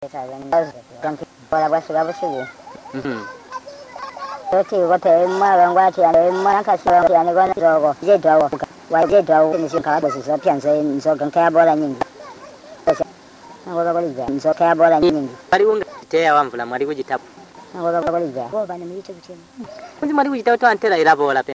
Oral History-Southern Zambia